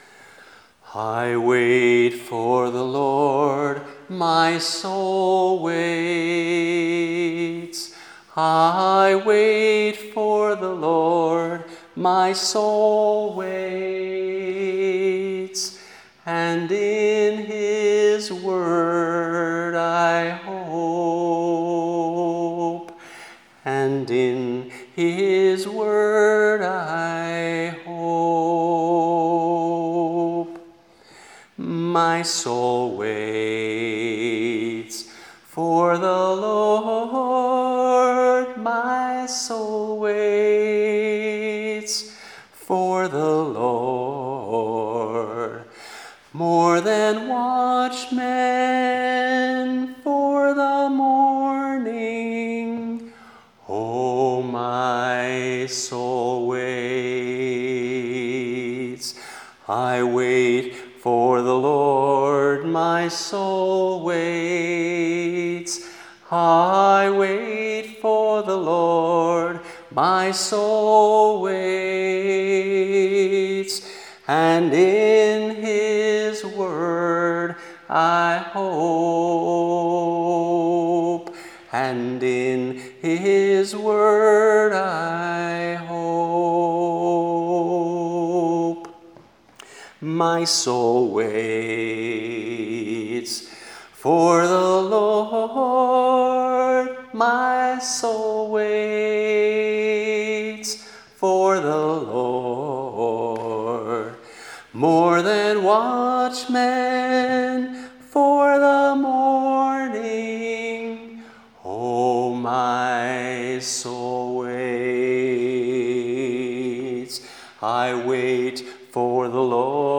(two part round, from Psalm 130: 5-6)
slowly, with expectation